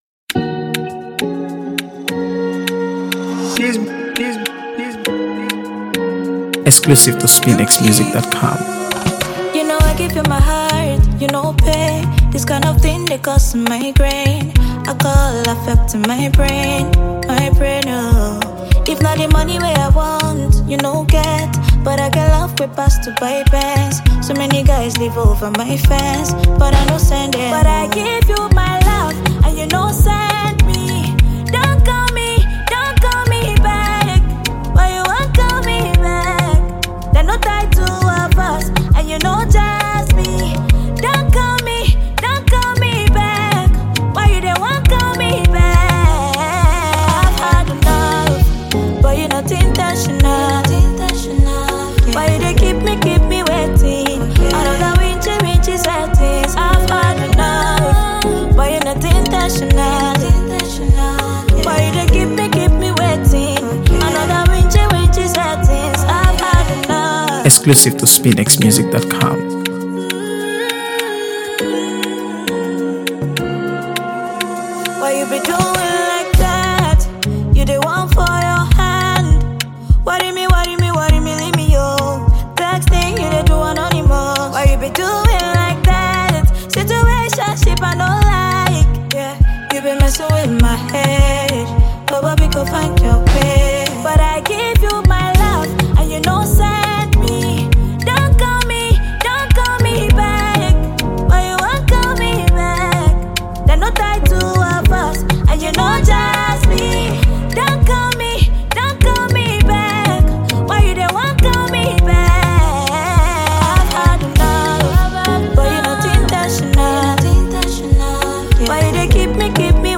AfroBeats | AfroBeats songs
catchy hooks and polished production